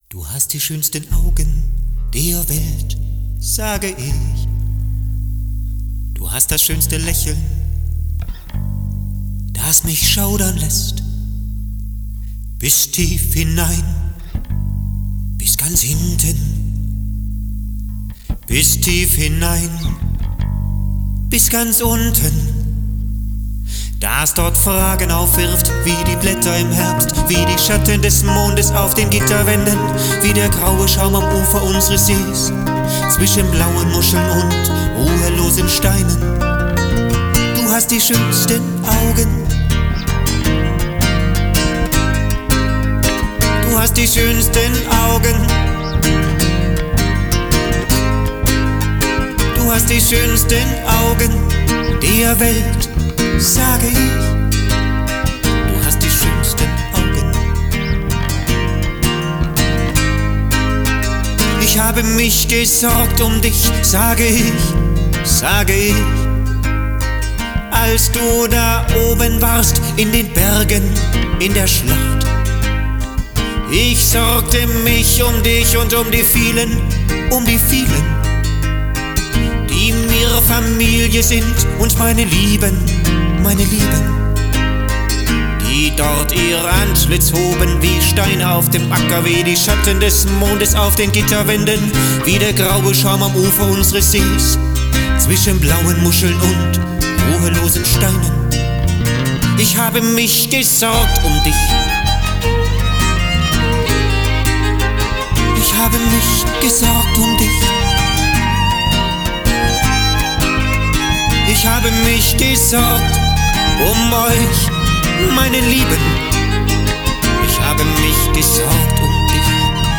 backvocals
bass
violine
studioaufnahmen & -schnitt